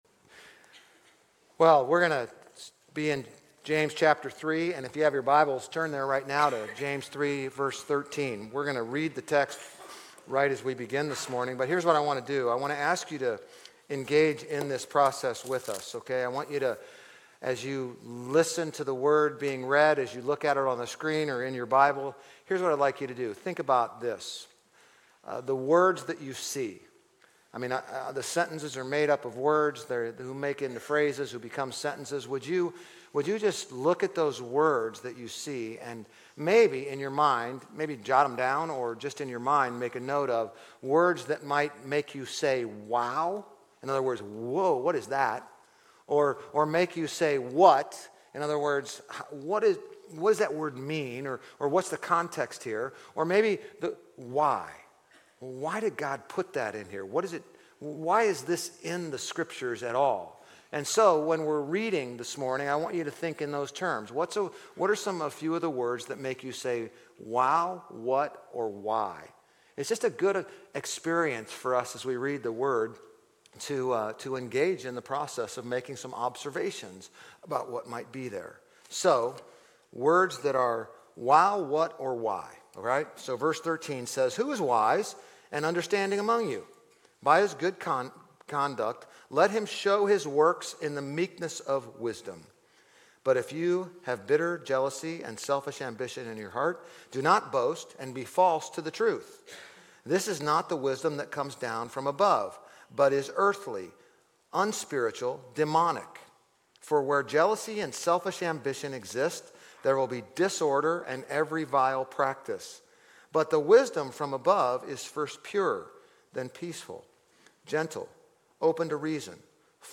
GCC-OJ-July-16-Sermon.mp3